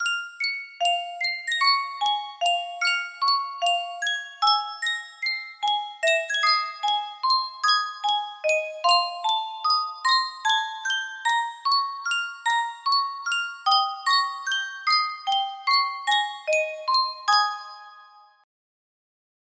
Yunsheng Caja de Musica - Si Vas Para Chile 3563 music box melody
Full range 60